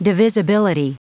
Prononciation du mot divisibility en anglais (fichier audio)
Prononciation du mot : divisibility